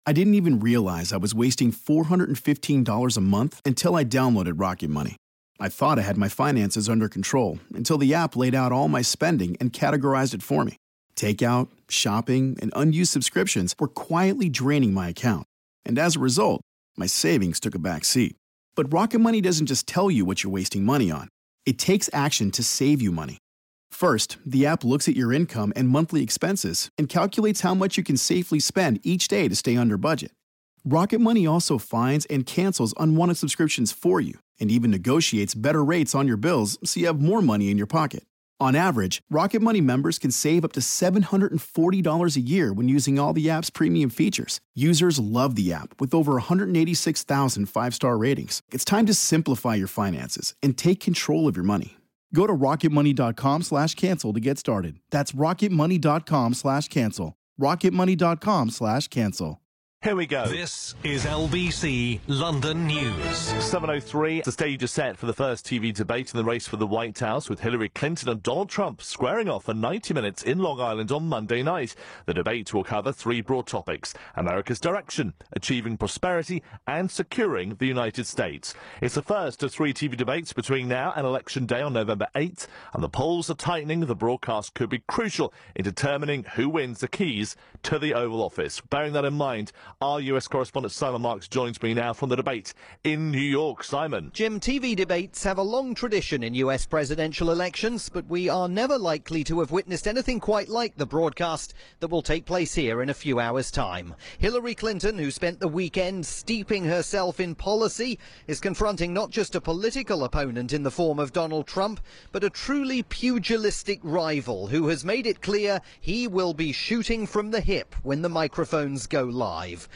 Here via the UK's rolling news station, LBC London News.